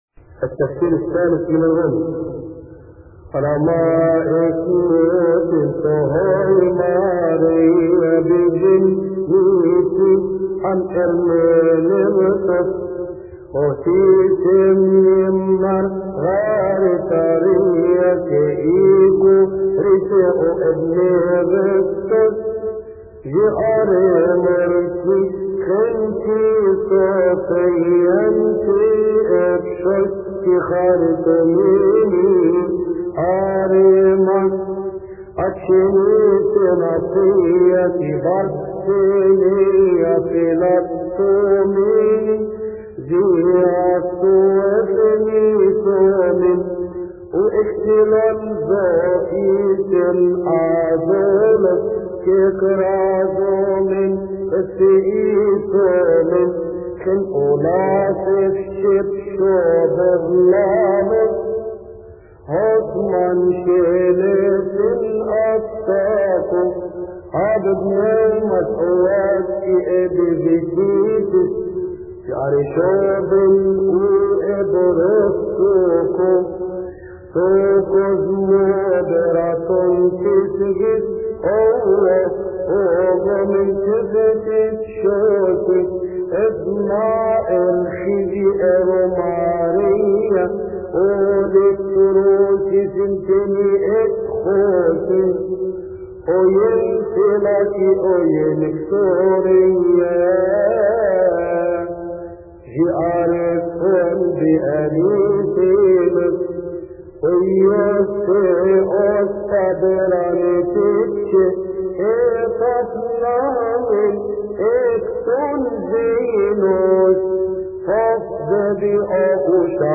يصلي في تسبحة عشية أحاد شهر كيهك
المرتل